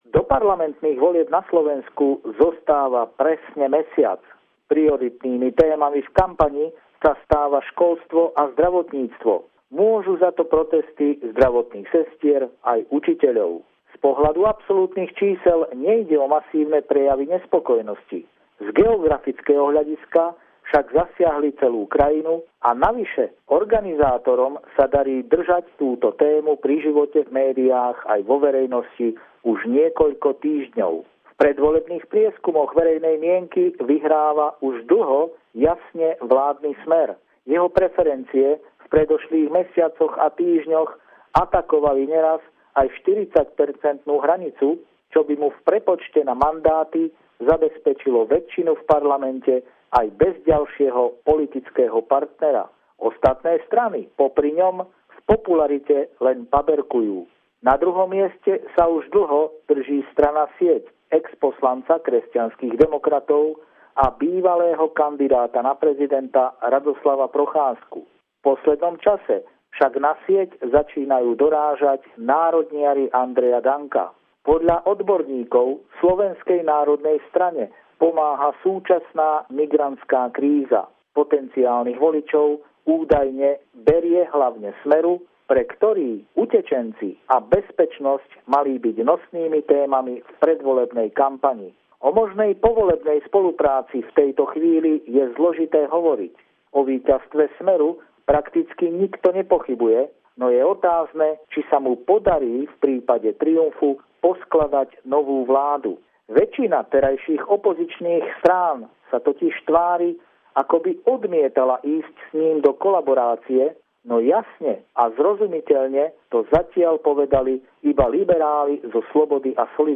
Pravidelný telefonát týždňa od nášho bratislavského kolegu